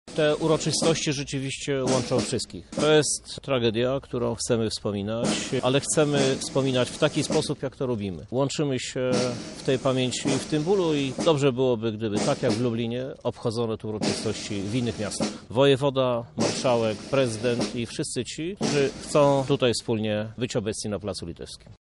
-mówi prezydent Lublina, Krzysztof Żuk.